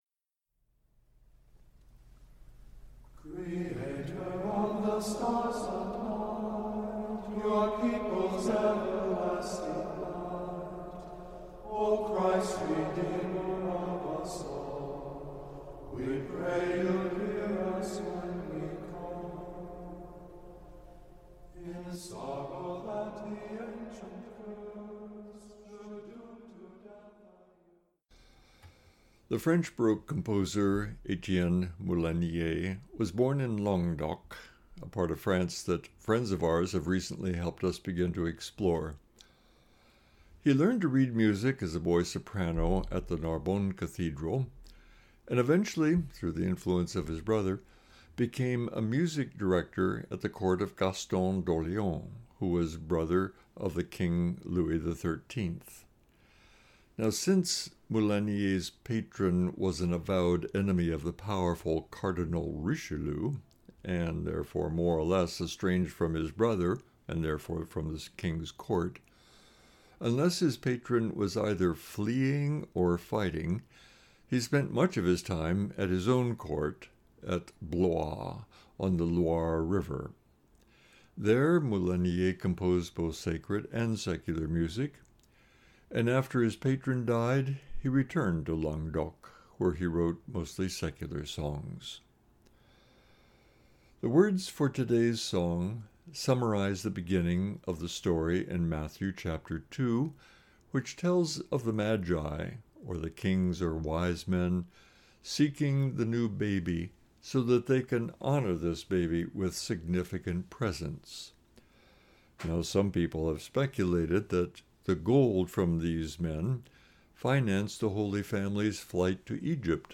2023-01-06 Meditation for Epiphany (Moulinié - Magi videntes stellam)